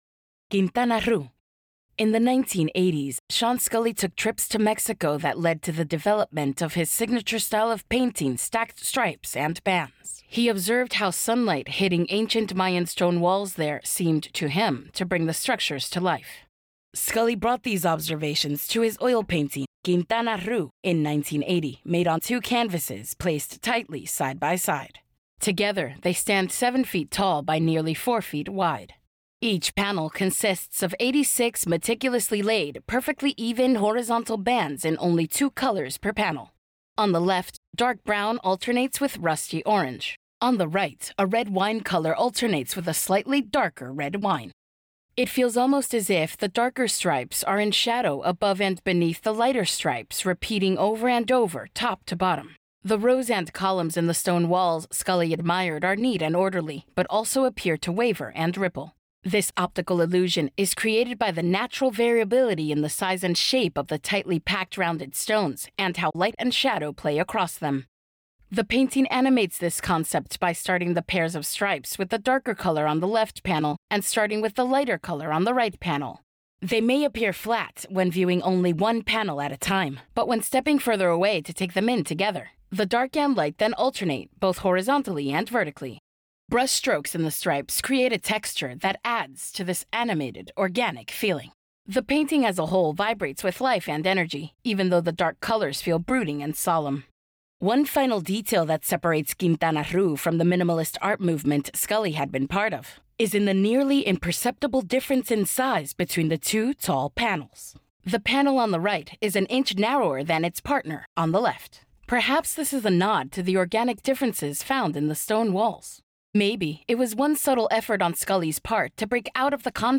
Audio Description (02:06)